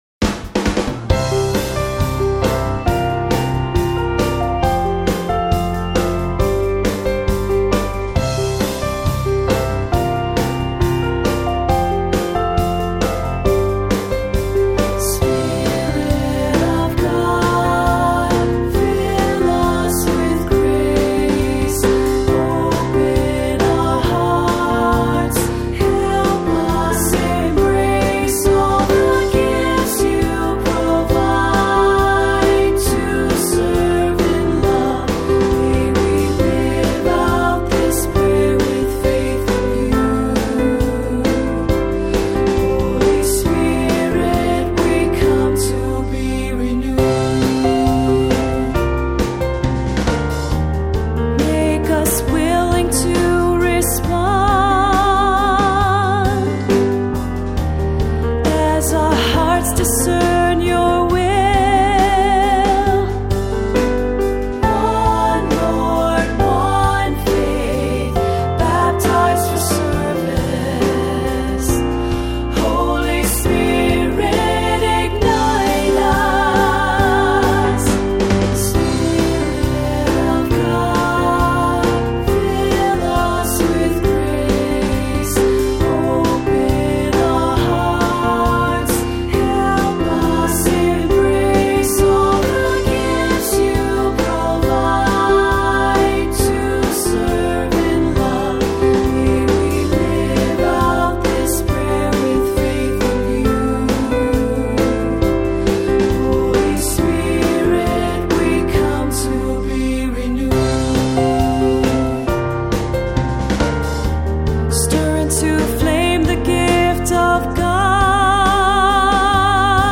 Voicing: Assembly, cantor,SAB